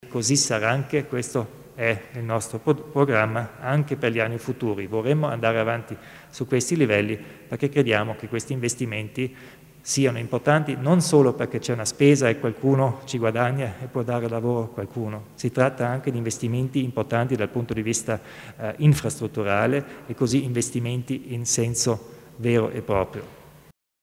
Il Presidente Kompatscher illustra gli investimenti per le infrastrutture ed edilizia